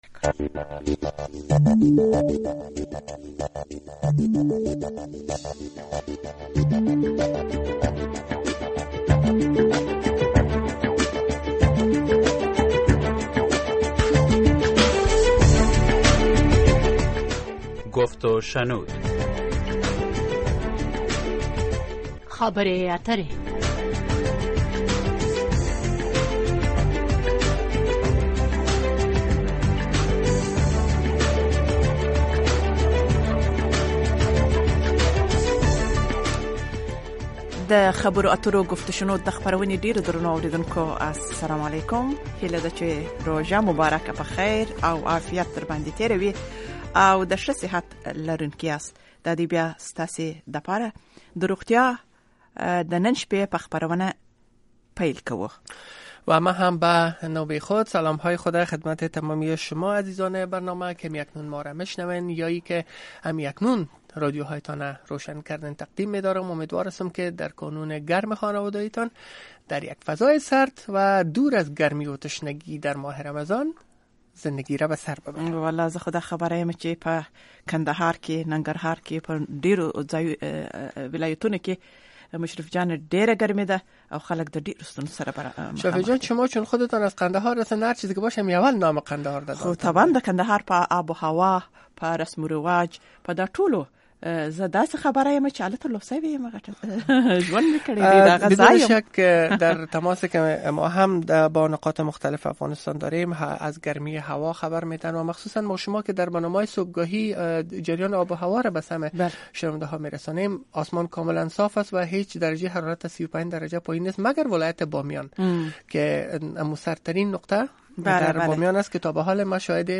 The URL has been copied to your clipboard No media source currently available 0:00 0:59:57 0:00 لینک دانلود 64 kbps | ام‌پی ۳ برای شنیدن مصاحبه در صفحۀ جداگانه اینجا کلیک کنید